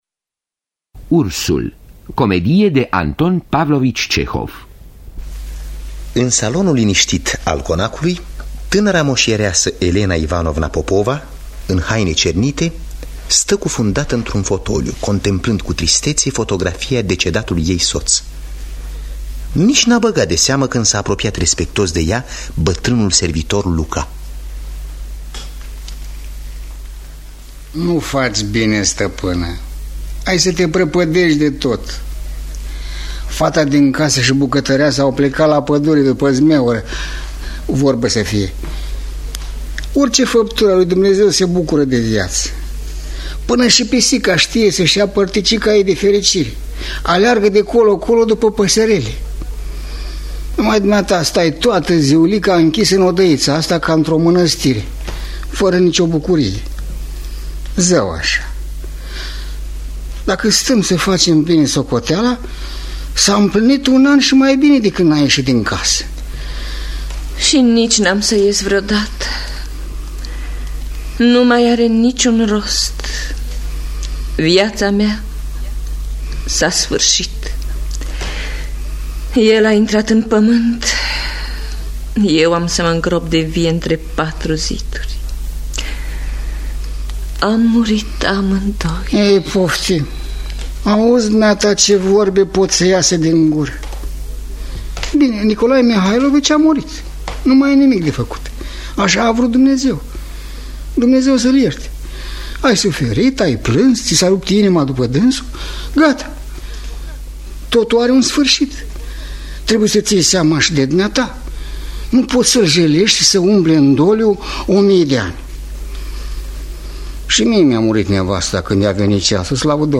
“Ursul” de Anton Pavlovici Cehov – Teatru Radiofonic Online